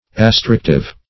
Search Result for " astrictive" : The Collaborative International Dictionary of English v.0.48: Astrictive \As*tric"tive\, a. Binding; astringent.